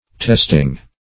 Testing \Test"ing\, n.